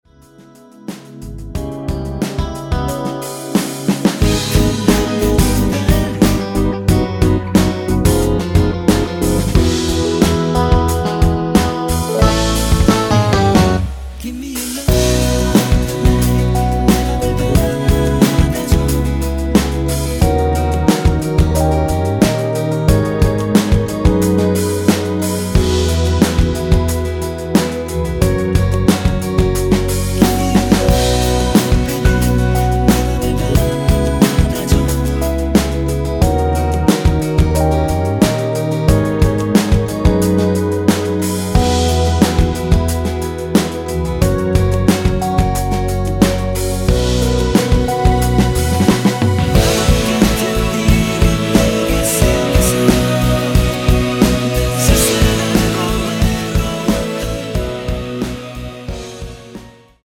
원키 코러스 포함된 MR입니다.
앞부분30초, 뒷부분30초씩 편집해서 올려 드리고 있습니다.
중간에 음이 끈어지고 다시 나오는 이유는